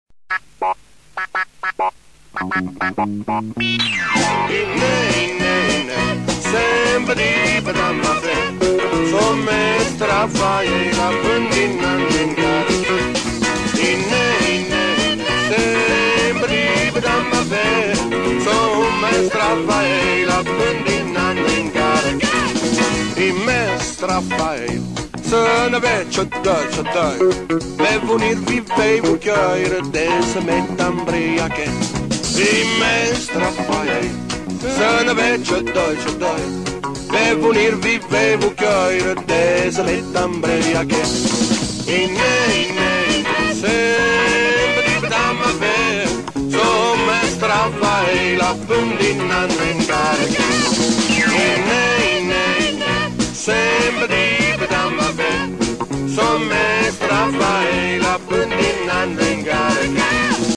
canto iterativo
batteria e percussioni
chitarra acustica ed elettrica, cori
basso e cori.
Alla fine di ogni strofa si ripete il ritornello.